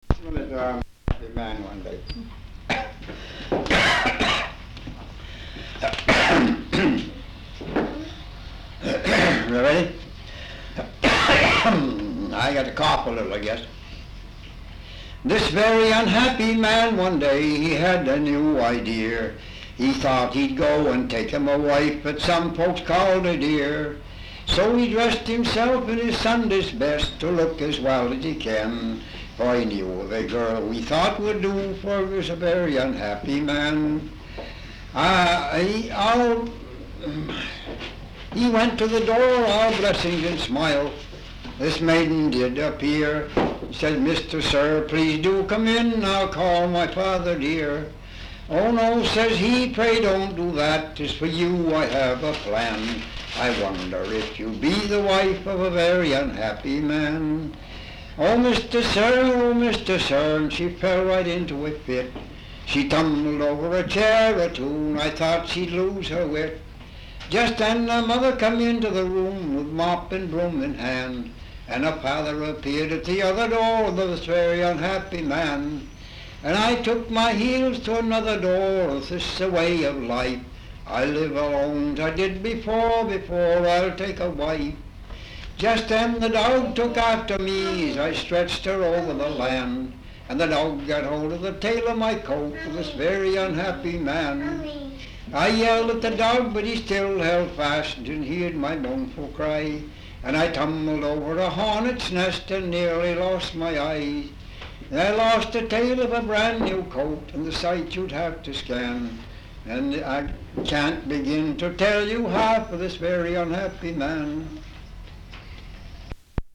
Folk songs, English--Vermont
sound tape reel (analog)
Dover, Vermont